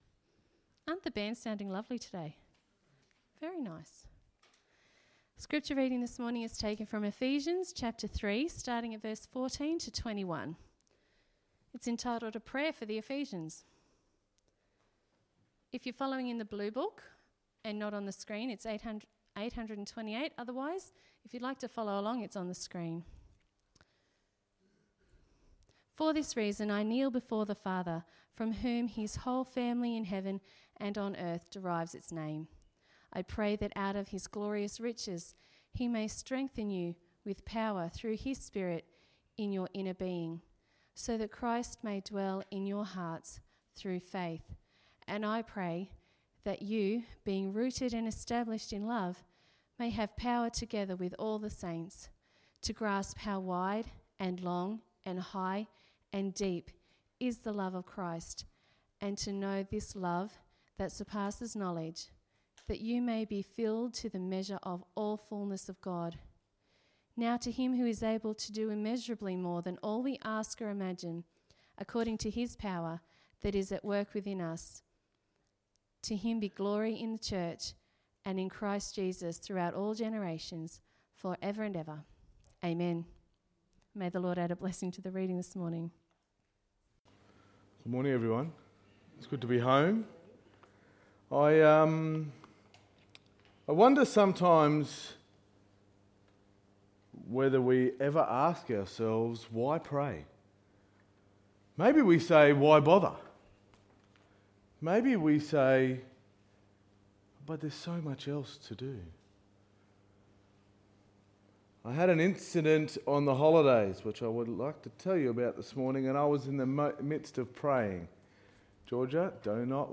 Sermon 13.10.2019